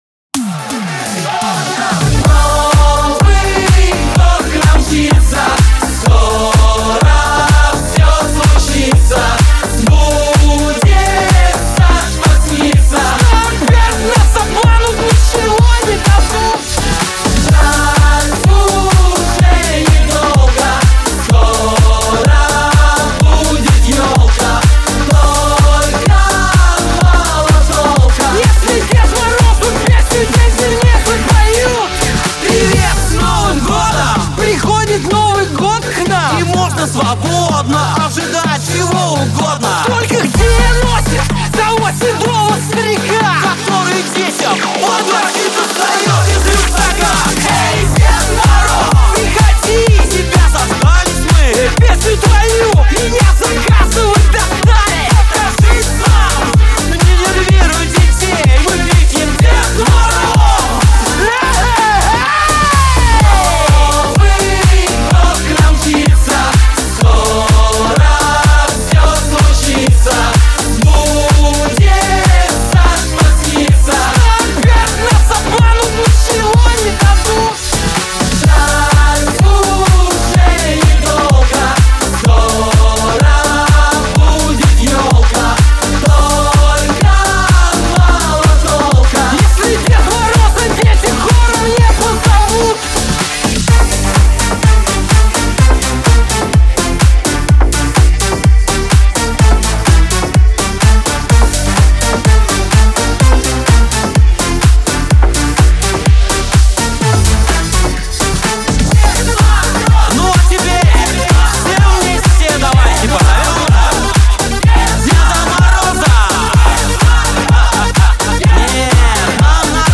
это яркий и зажигательный трек в жанре поп